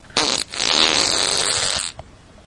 大黄蜂放屁
描述：放屁
标签： 外星人 爆炸 flatulation 肠胃气胀 气体 激光 噪声 poot 怪异
声道立体声